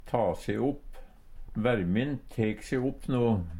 DIALEKTORD PÅ NORMERT NORSK ta se opp auke Infinitiv Presens Preteritum Perfektum å ta se opp tek se opp tok se opp tikji se opp Eksempel på bruk Vermin tek se opp no. Hør på dette ordet Ordklasse: Verb Attende til søk